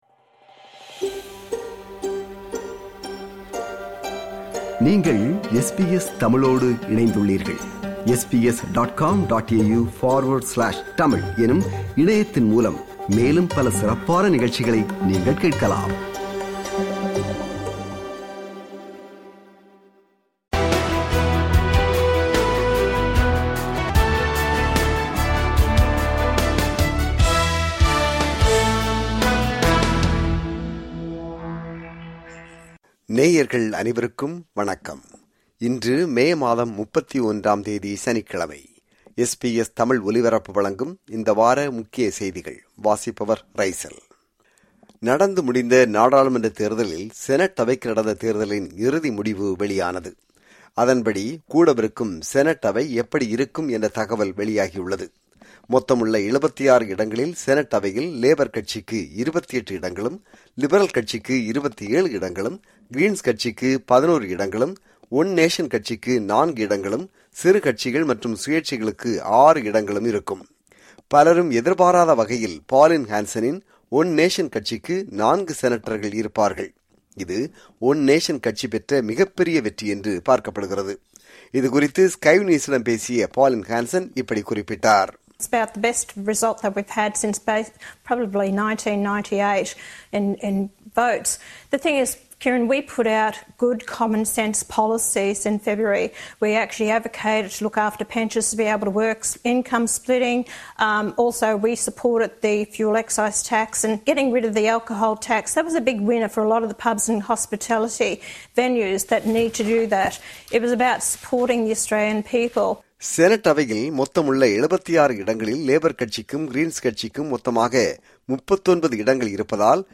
ஆஸ்திரேலிய, உலக செய்திகளின் இந்த வார தொகுப்பு